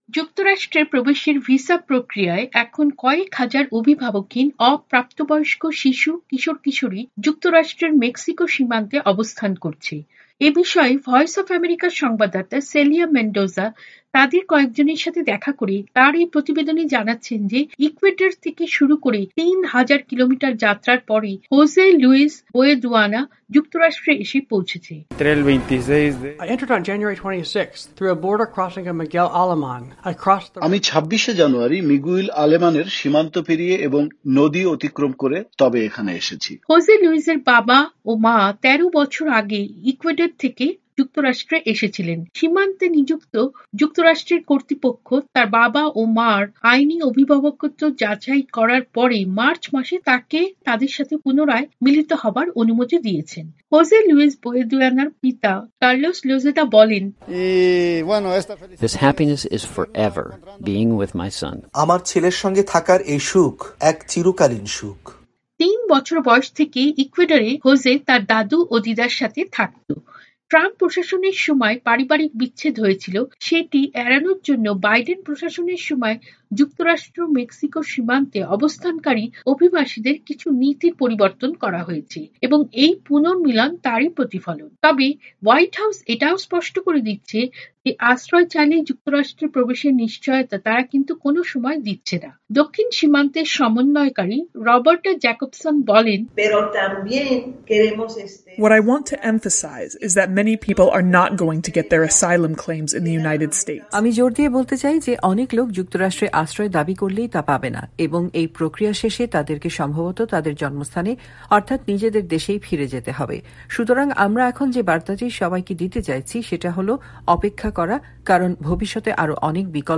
প্রতিবেদন পড়ে শোনাচ্ছন